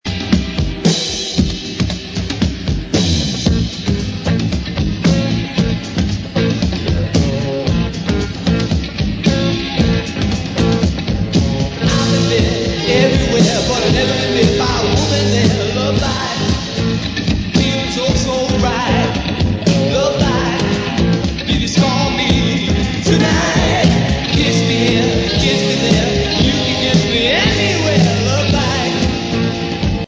lead vocals, drums
guitar, bass
Recorded at Record Plant studios, early 1977.